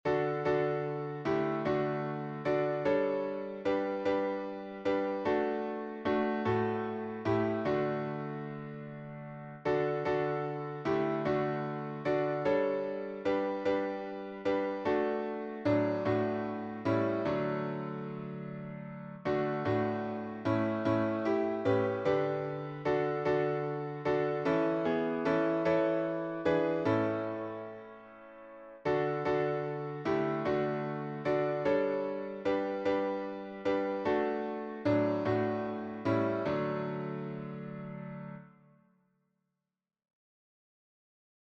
A cappella
SATB